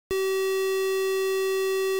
triangle_pitch.wav